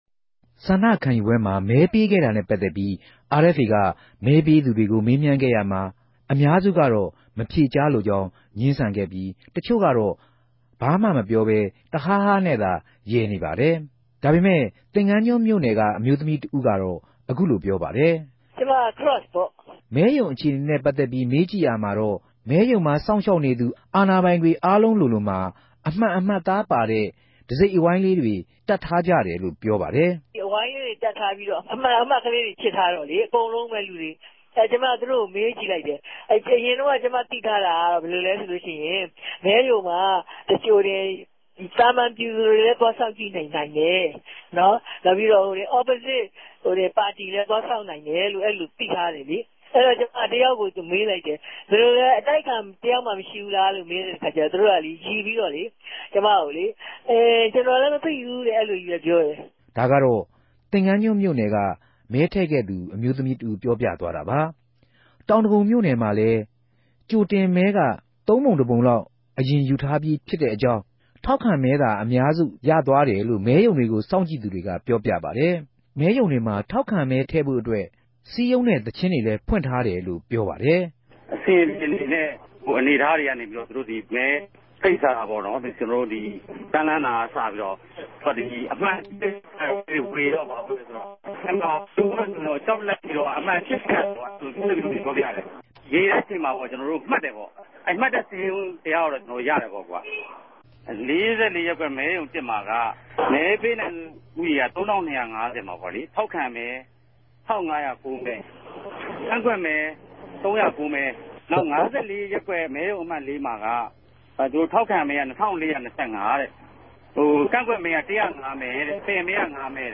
ဆ္ဋိံခံယူပြဲနဲႛ ပတ်သက်္ဘပီးမဵက်ူမင်သက်သေနဲႛ မေးူမန်းခဵက်။